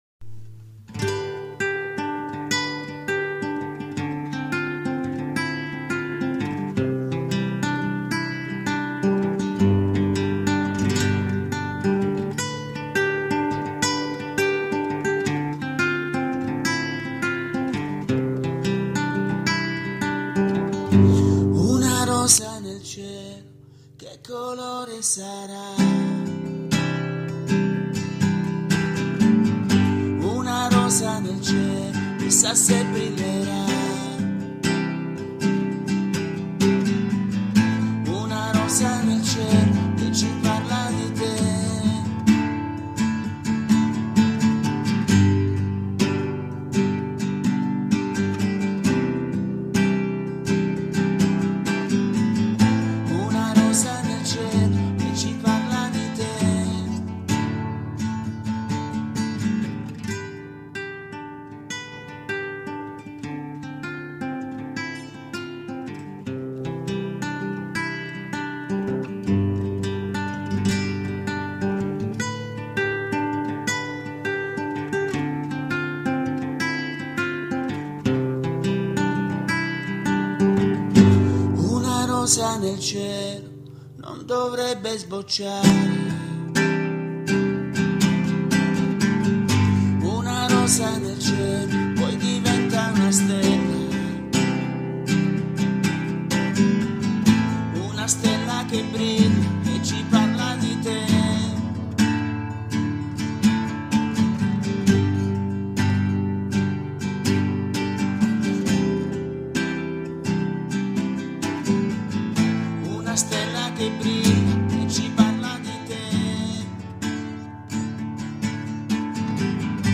Voce, musica e testo